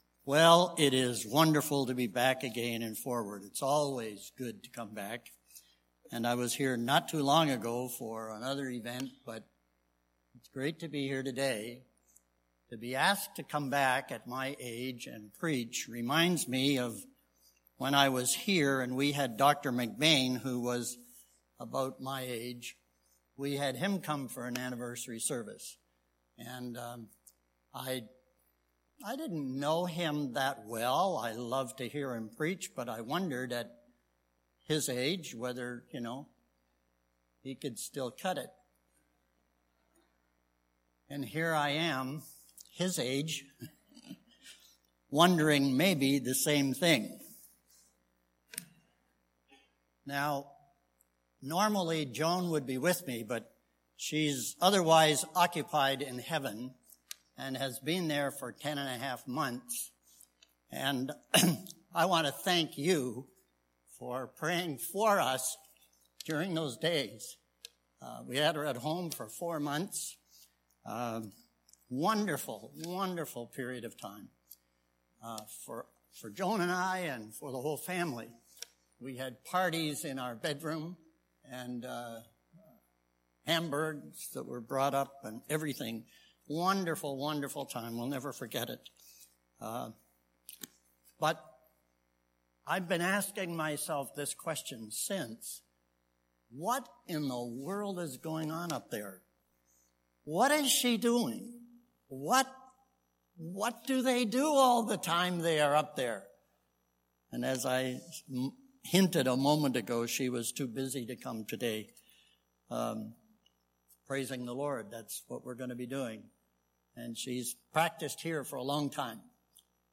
Sermons
Anniversary Sunday 2017